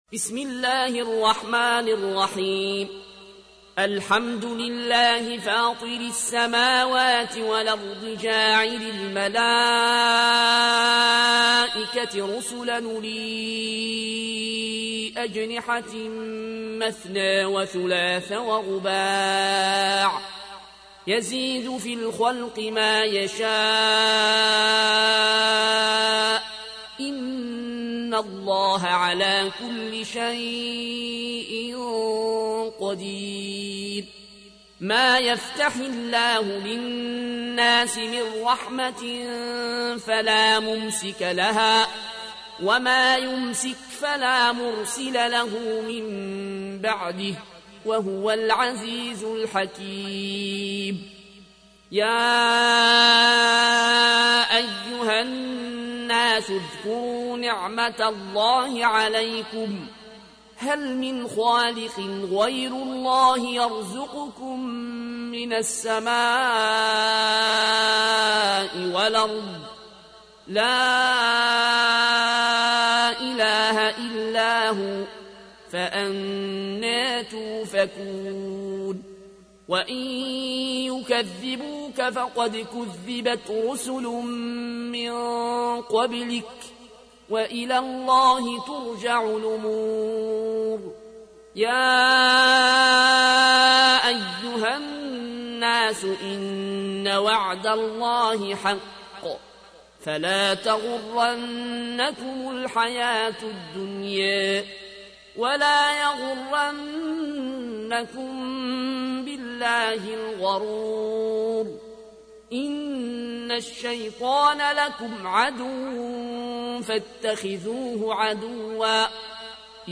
تحميل : 35. سورة فاطر / القارئ العيون الكوشي / القرآن الكريم / موقع يا حسين